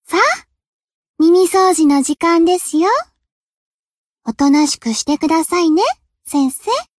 贡献 ） 分类:蔚蓝档案 分类:蔚蓝档案语音 协议:Copyright 您不可以覆盖此文件。